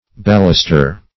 Search Result for " ballister" : The Collaborative International Dictionary of English v.0.48: Ballister \Bal"lis*ter\ (b[a^]l"l[i^]s*t[~e]r or b[a^]l*l[i^]s"t[~e]r), n. [L. ballista.